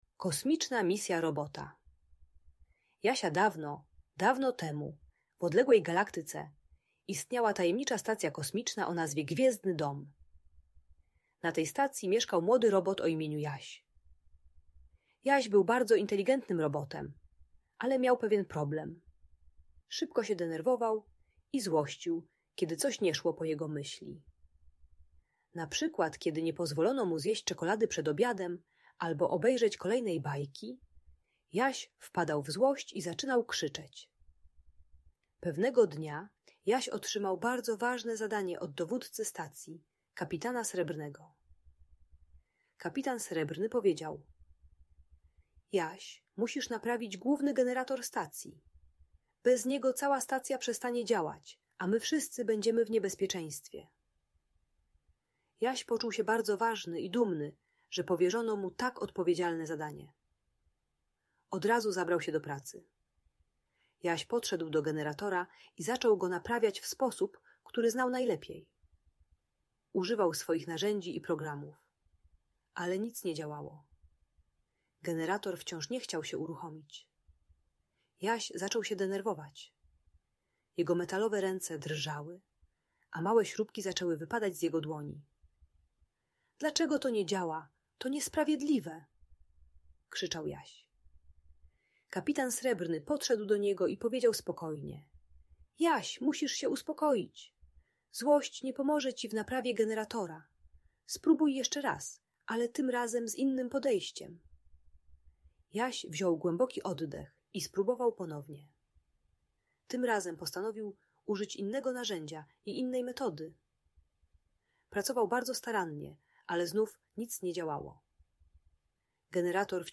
Kosmiczna Misja Robota Jasia - Audiobajka